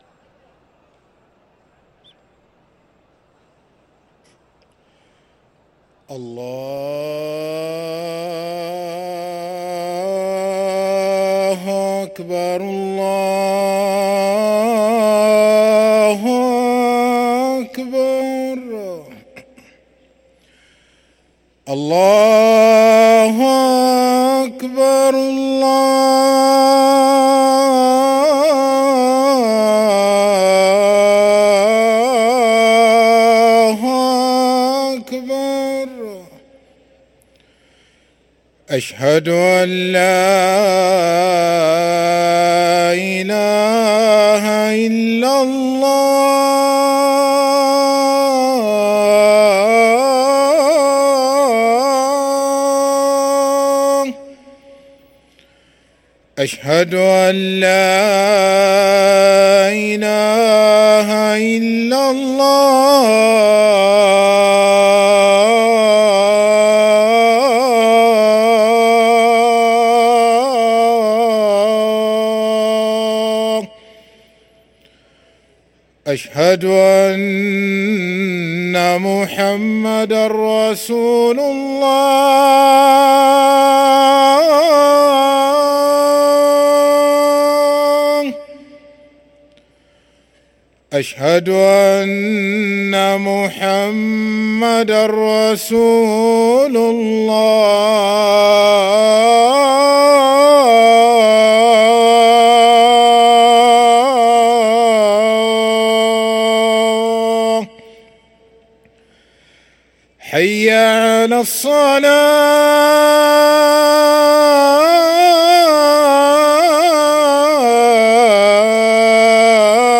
أذان الظهر للمؤذن سعيد فلاته الاثنين 26 صفر 1445هـ > ١٤٤٥ 🕋 > ركن الأذان 🕋 > المزيد - تلاوات الحرمين